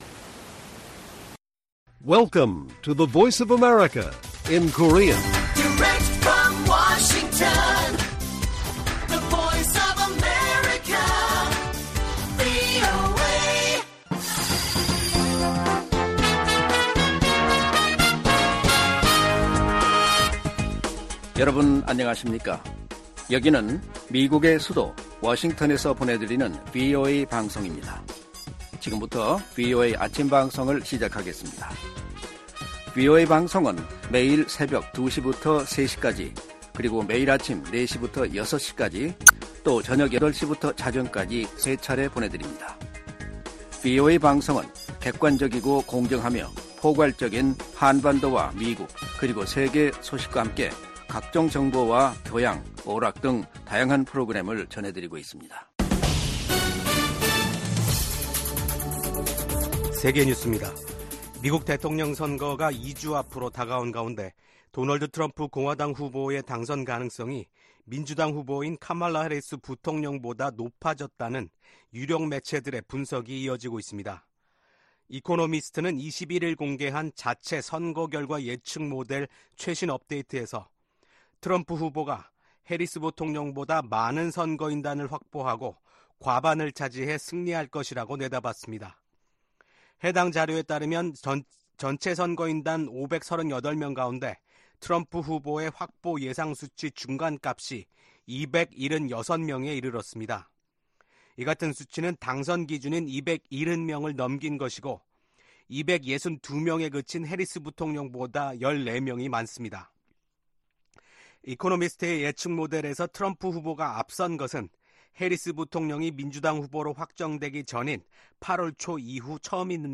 세계 뉴스와 함께 미국의 모든 것을 소개하는 '생방송 여기는 워싱턴입니다', 2024년 10월 23일 아침 방송입니다. 중동 순방 일정을 시작한 토니 블링컨 미국 국무장관이 블링컨 장관이 22일 베냐민 네타냐후 이스라엘 총리를 만나 역내 현안을 논의했습니다. 미국 대통령 선거가 2주 앞으로 다가온 가운데 민주당 후보인 카멀라 해리스 부통령과 공화당 후보 도널드 트럼프 전 대통령이 경합주를 돌면서 치열한 유세를 펼치고 있습니다.